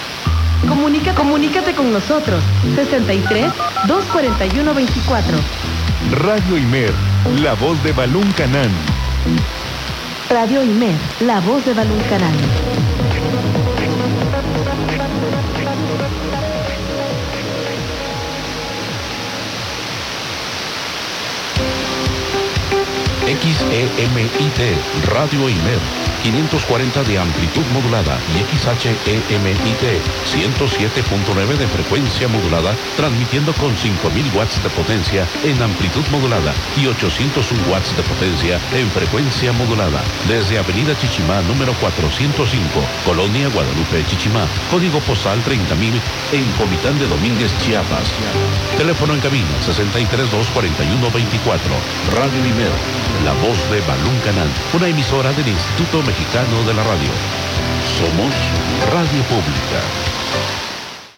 Radioescucha: Identificación de La Voz de Balún Canán XEMIT 540 Khz.
Receptor: Kenwood R-600 Antena: Hilo largo (10 metros de longitud por 6 metros de alto)